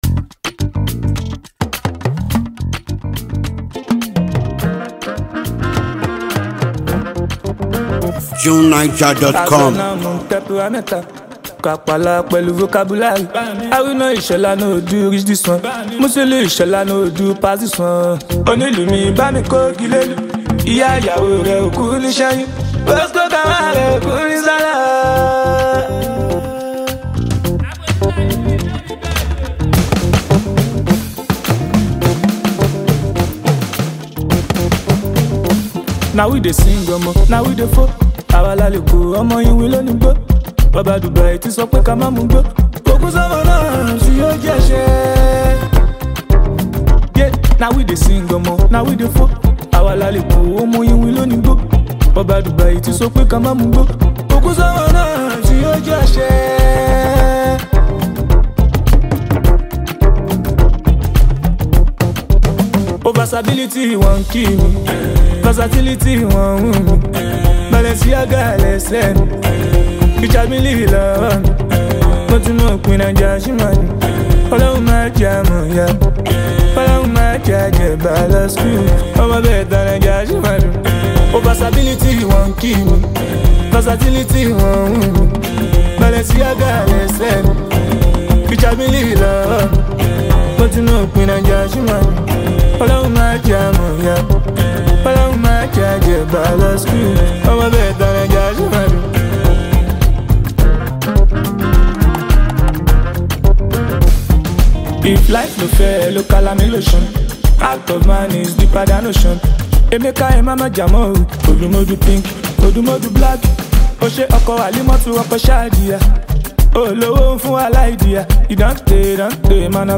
catchy song
Afropop